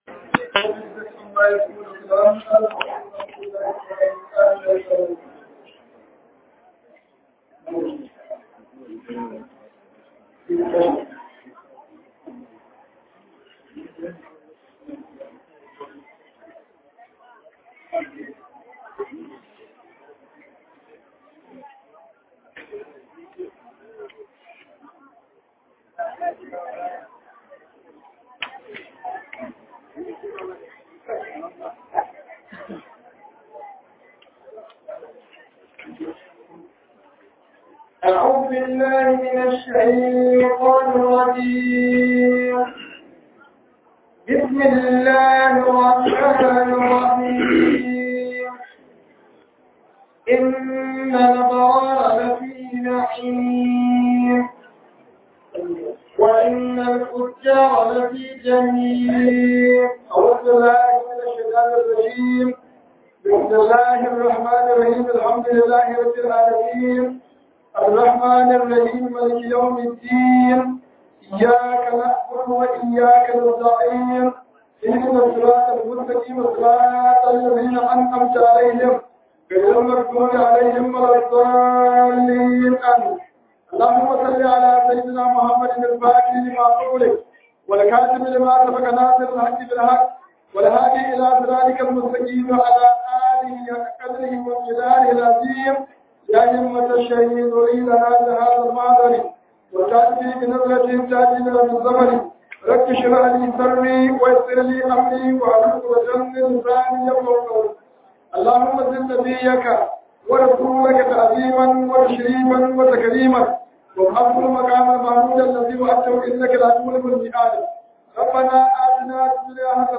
RAMADAN TAFSIR 2024